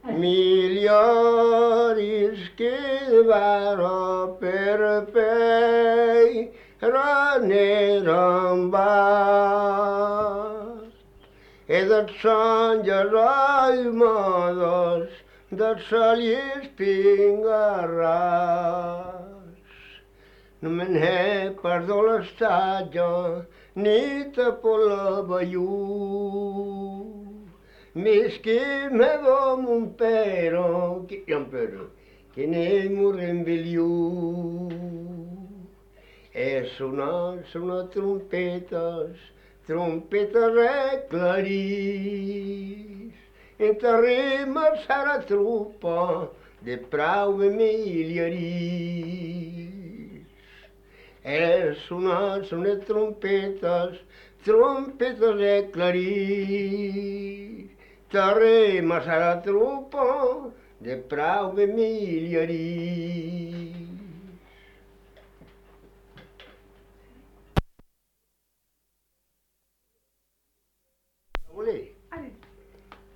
Aire culturelle : Bigorre
Lieu : Lesponne (lieu-dit)
Genre : chant
Effectif : 1
Type de voix : voix d'homme
Production du son : chanté